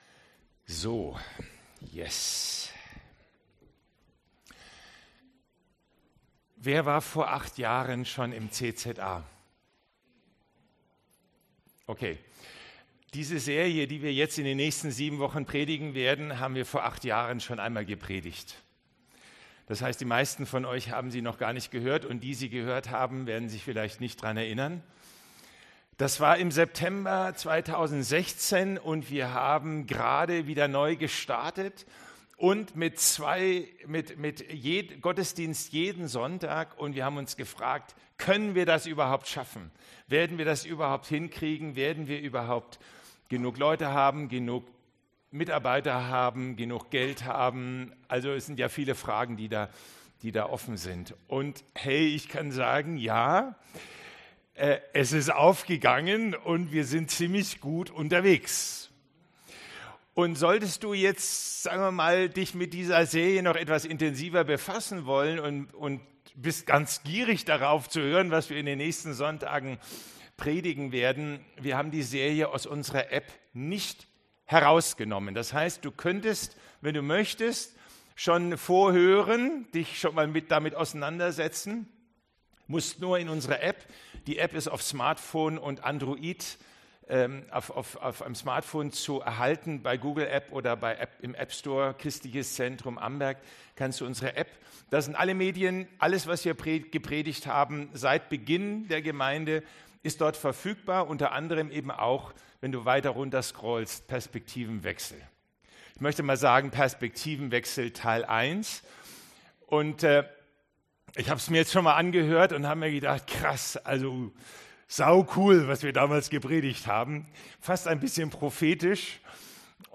Hier kannst du die Predigt nochmal anhören oder lade dir die CZA App herunter,...